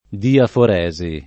diaforesi [ diafor $@ i ]